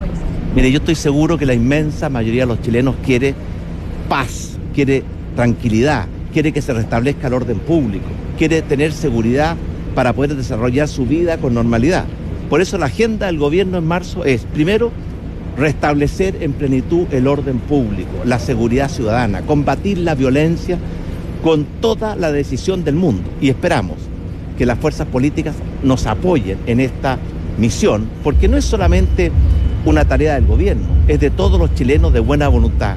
Tras aprovechar la instancia para reunirse con el rey de España, Felipe VI y con el presidente de Colombia, Iván Duque, el presidente Piñera ofreció un breve punto de prensa en la Plaza Independencia de Montevideo.